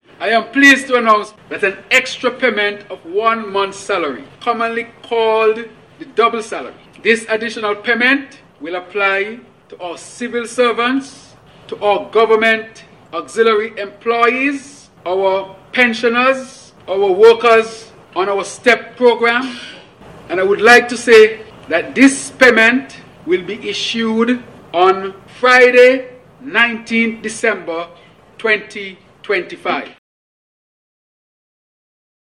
This he said, while presenting the 2026 Budget Address in the National Assembly on Tuesday Dec. 16th, which was held under the theme “Investing in People and Progress.”